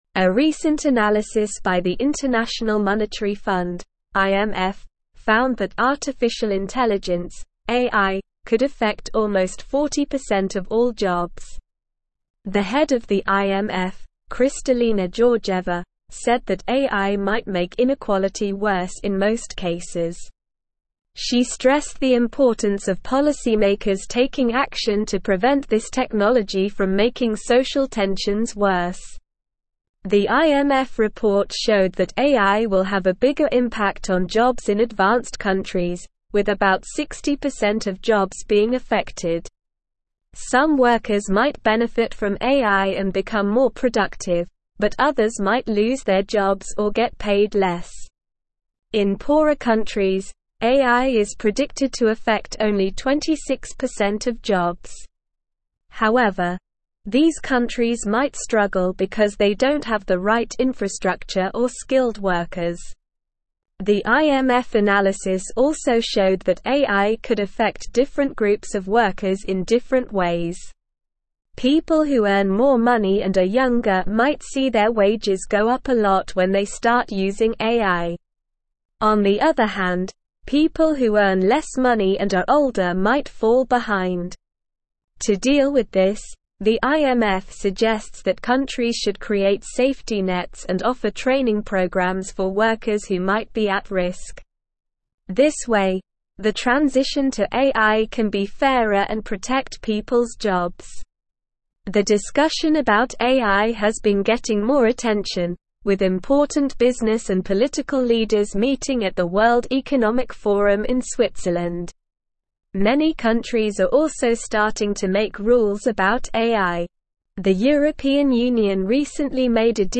Slow
English-Newsroom-Upper-Intermediate-SLOW-Reading-AIs-Impact-on-Jobs-and-Inequality-IMF-Analysis.mp3